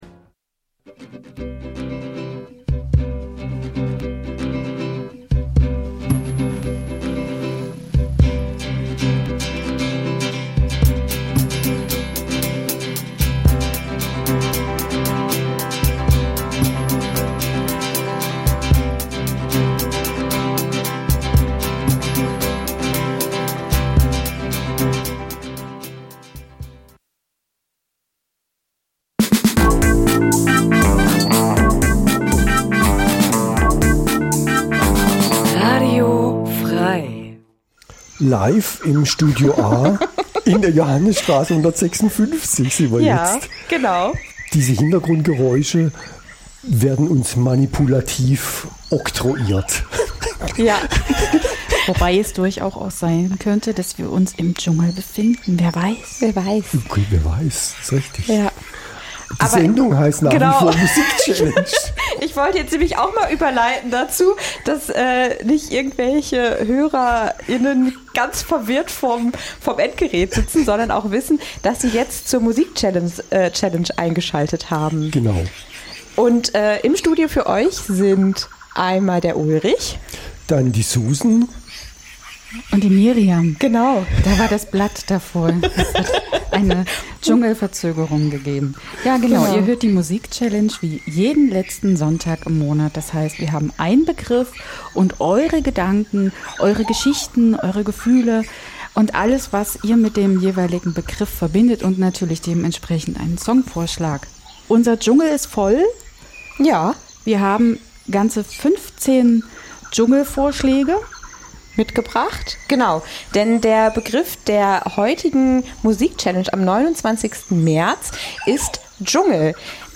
Ein Begriff, viele Songs aus unterschiedlichen Genres.